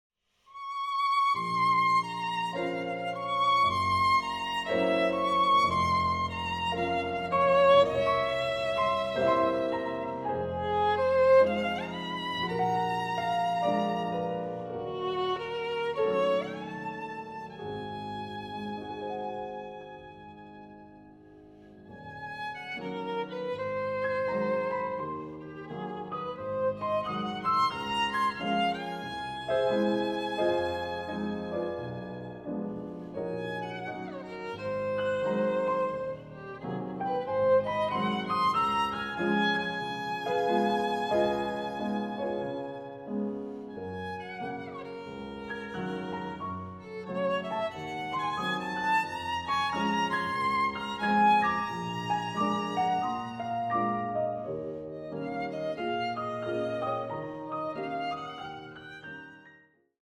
Violine
Klavier